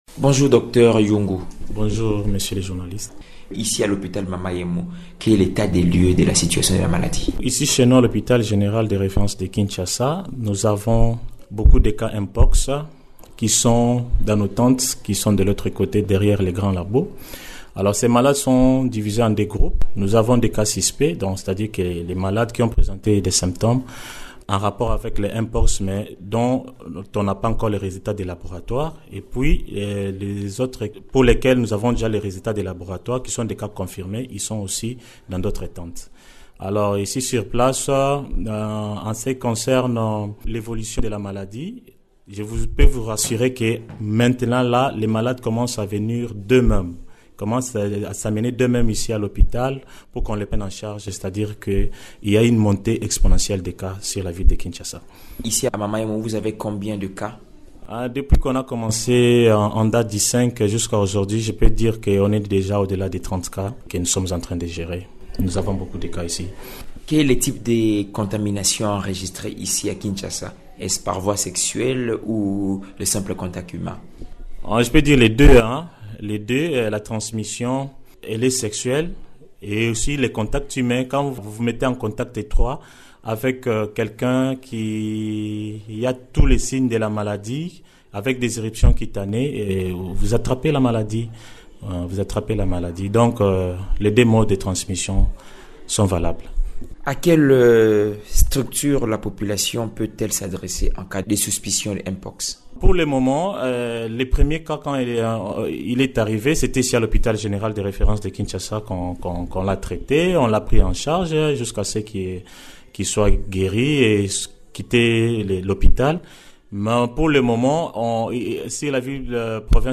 Il répond aux questions